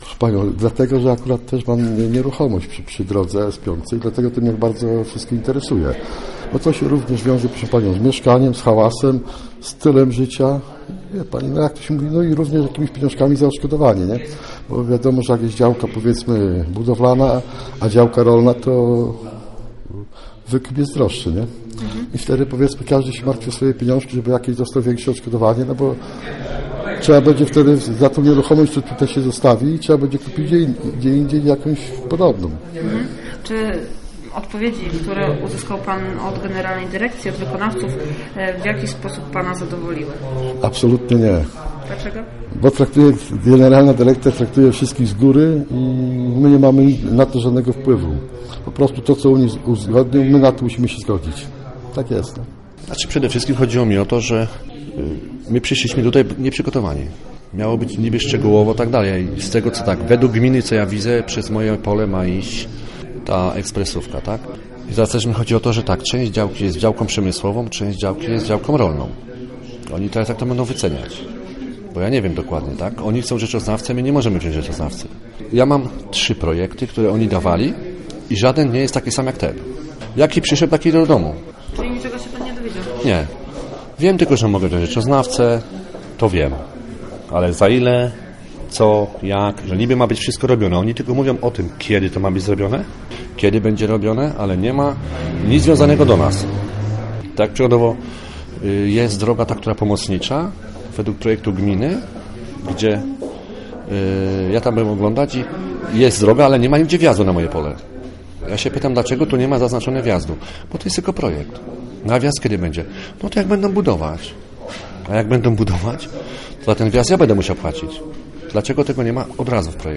4 kwietnia w Żnińskim Domu Kultury odbyło się spotkanie dotyczące budowy drogi ekspresowej S-5 z przedstawicielami bydgoskiego oddziału Generalnej Dyrekcji Dróg Krajowych i Autostrad oraz wykonawców robót.